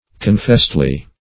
confestly - definition of confestly - synonyms, pronunciation, spelling from Free Dictionary Search Result for " confestly" : The Collaborative International Dictionary of English v.0.48: Confestly \Con*fest"ly\, adv.